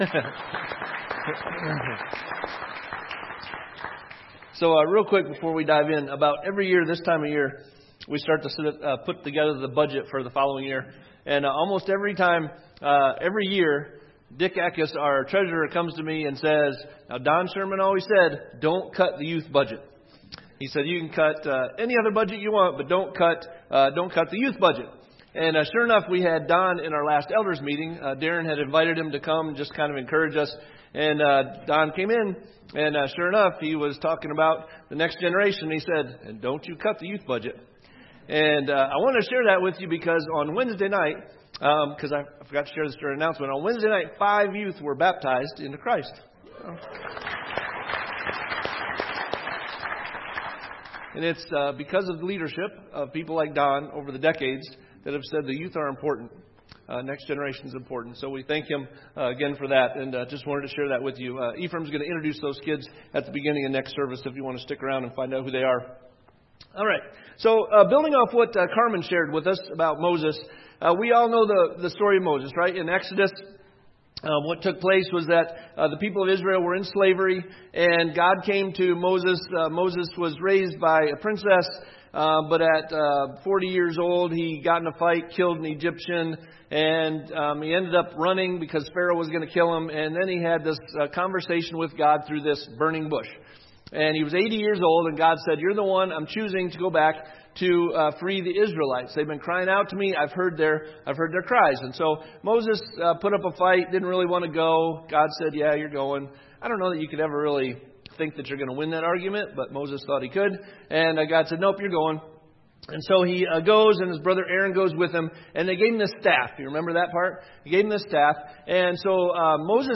Troubles Keepin It Real 2 Timothy Watch Listen Save Sermon Series